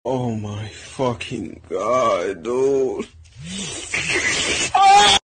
pigeon bng meme. sound effects free download